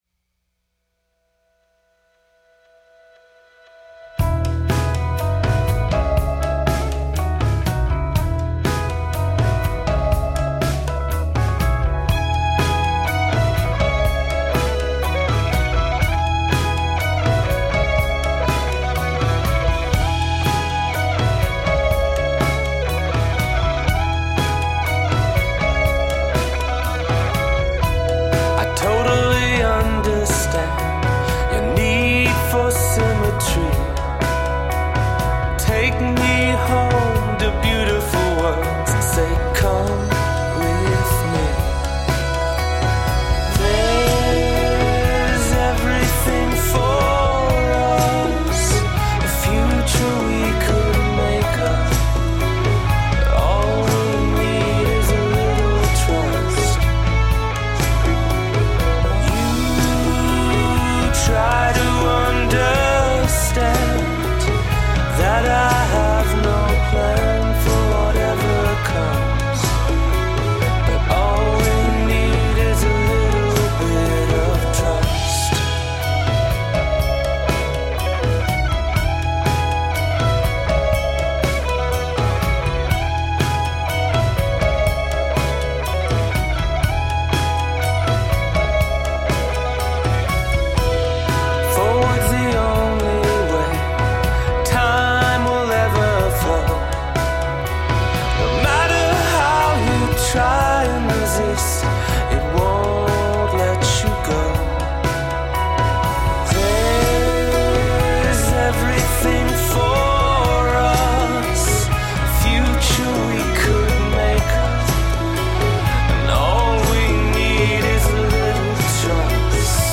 Hook-laden anthems of survival and triumph.
Tagged as: Electro Rock, Alt Rock, Darkwave, Downtempo, Goth